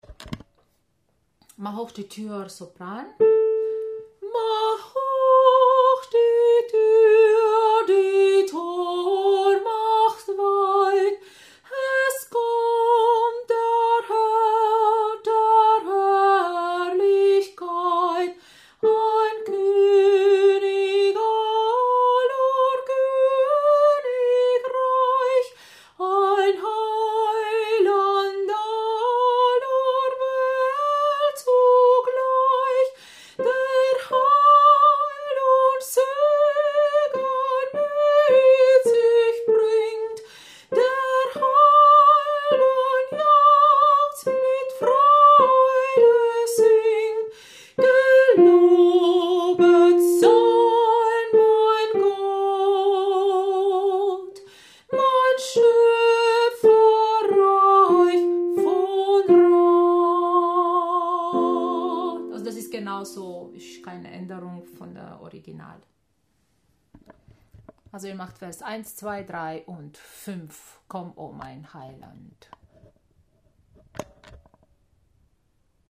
02 - Sopran - ChorArt zwanzigelf - Page 7